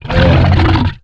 horror
Monster Growl 16